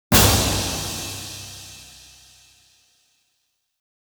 下のサンプルは、手を加えていない状態のシンバルと、16kHz以上をカットしたシンバルです。
元のシンバルのトゥルーピークは+0.8dBですが、16kHz以上をカットしたシンバルは-0.1dBになっています。
※音量注意。結構大きいです。
・元のシンバル（+0.8dBTP/-15LUFS）
cymbal-tp-sample-pre.wav